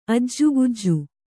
♪ ajjugujju